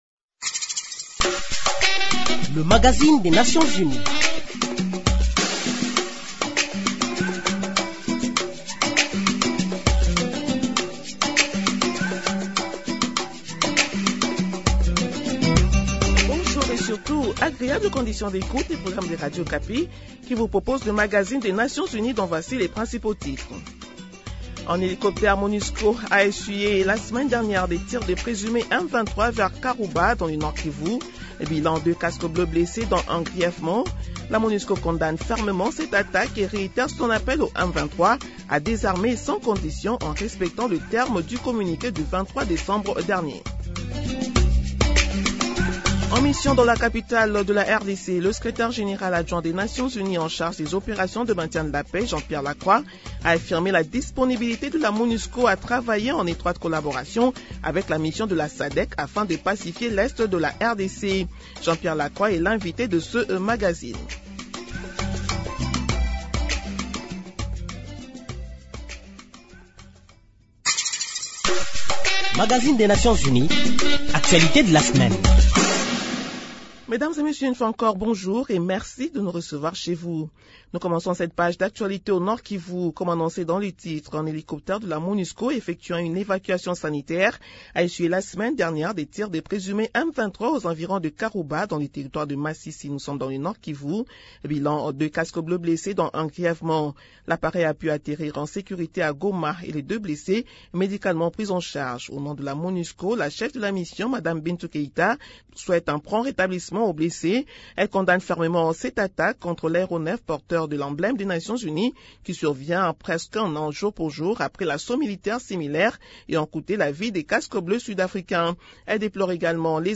Nouvelle en bref Plus de 135 000 personnes sont en déplacement vers la ville de Goma, estiment les acteurs humanitaires.
Ainsi l’accueil des déplacés vers la ville de Goma reste toujours difficile, faute d’espace disponible, a déclaré OCHA dans son rapport de situation rendu public le mercredi dernier. Invité Dans ce second entretien, le magazine des Nations unies reçoit comme invité, le Secrétaire général adjoint des Nations unies, en charge des opérations du maintien de la paix, Jean-Pierre Lacroix.